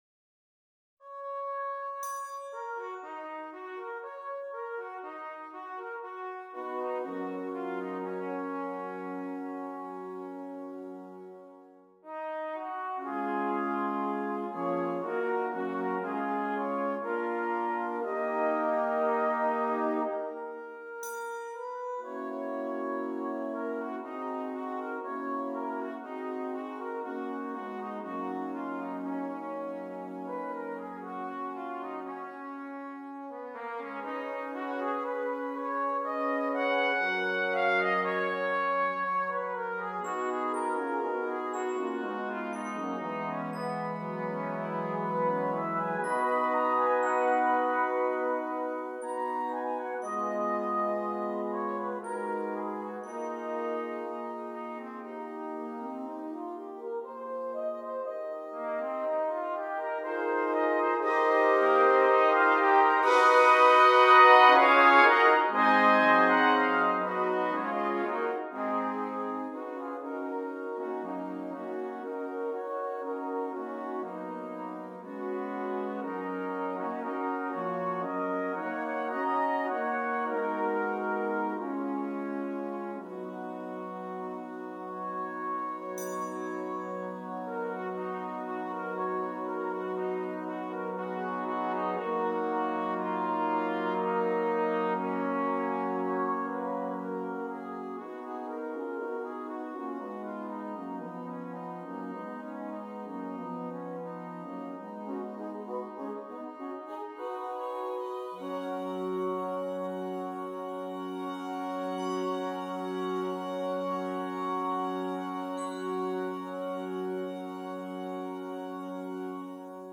8 Trumpets